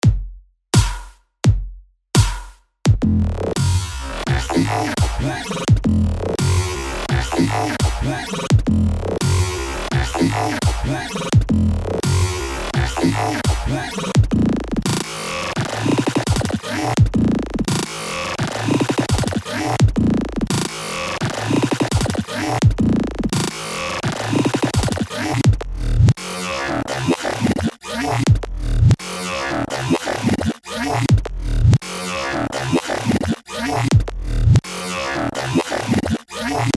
Обработан только кик и снейр: Вложения Drums_transformations_-_1.mp3 Drums_transformations_-_1.mp3 1,4 MB · Просмотры: 103